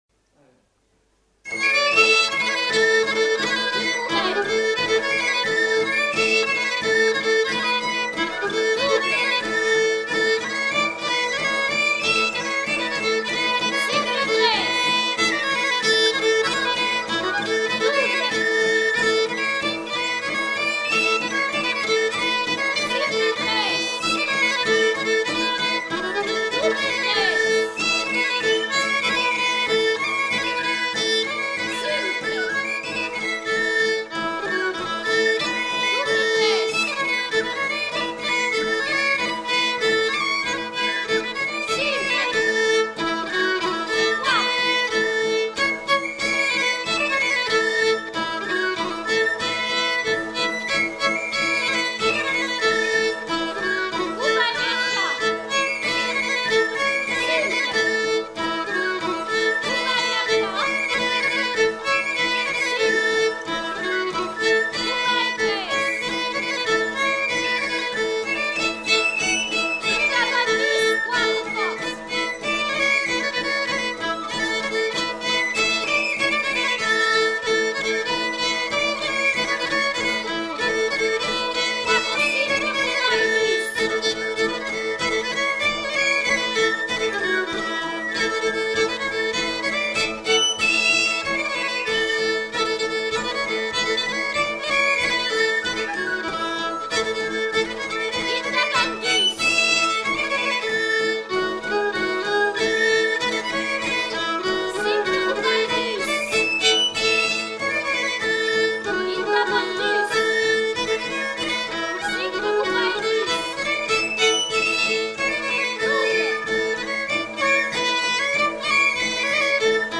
Aire culturelle : Béarn
Lieu : Bielle
Genre : morceau instrumental
Instrument de musique : violon ; flûte à trois trous ; tambourin à cordes
Danse : mochico